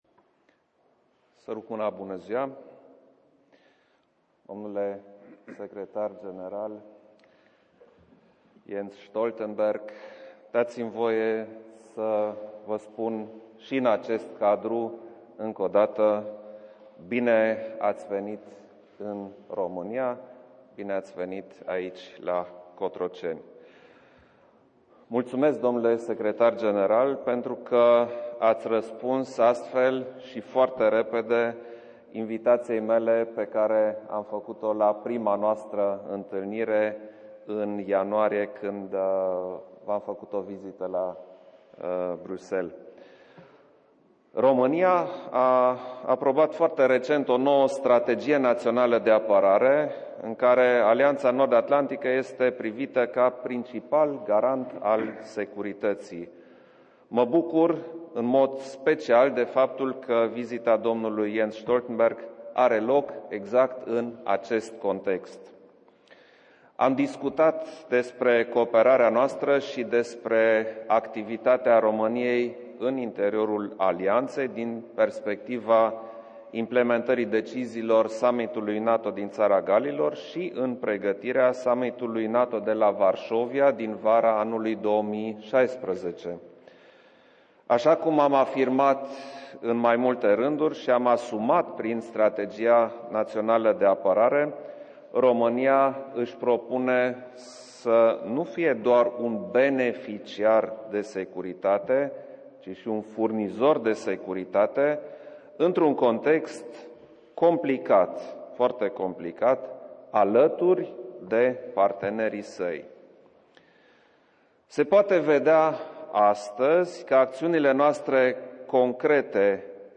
ORIGINAL - Joint Press conference with NATO Secretary General Jens Stoltenberg and the President of Romania, Klaus Werner Iohannis